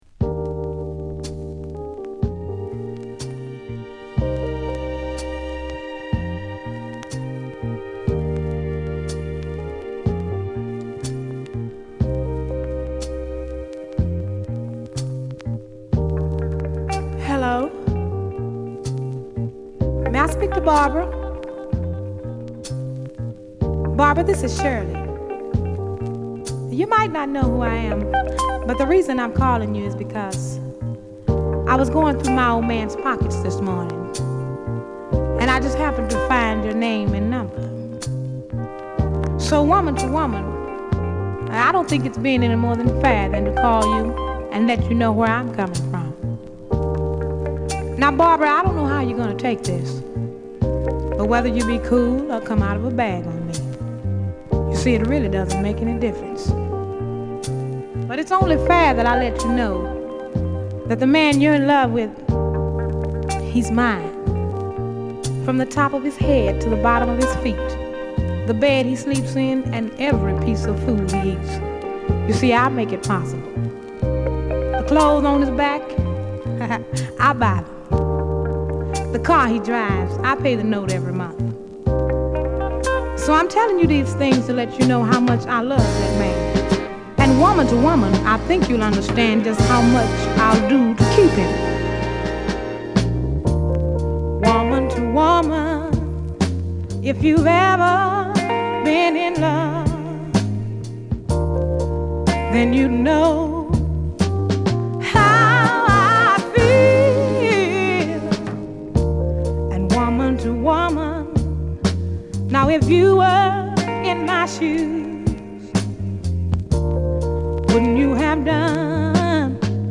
しっとりしたイントロから始まる激メロウなグッド・レディ・ソウル！